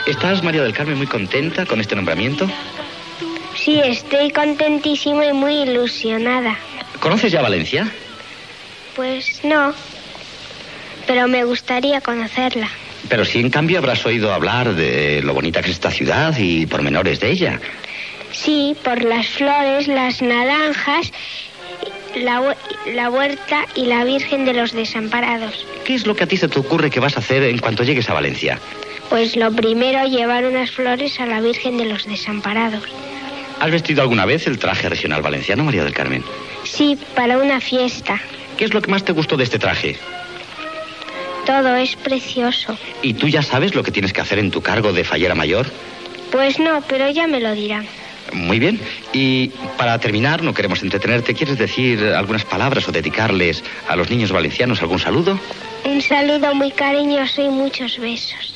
Entrevista a María del Carmen Martínez Bordiu, fallera major de València